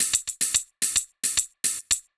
UHH_ElectroHatB_110-03.wav